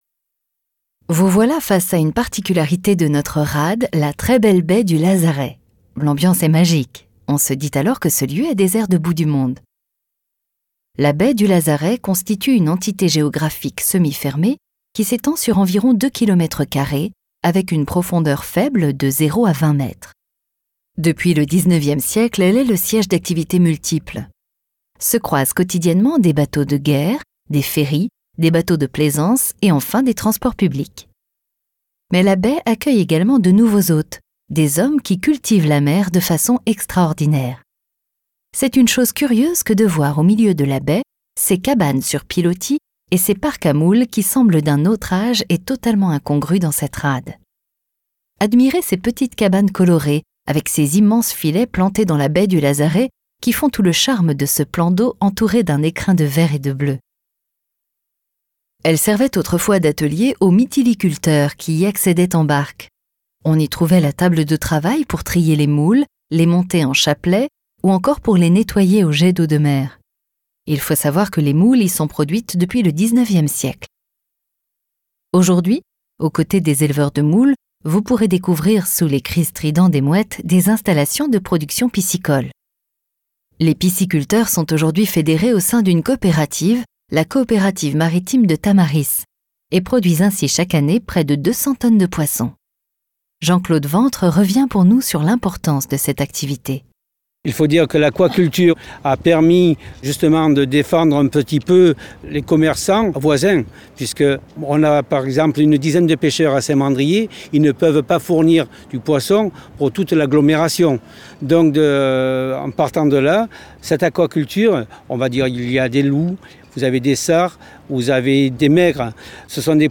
Audio-guide Saint-Mandrier-sur-Mer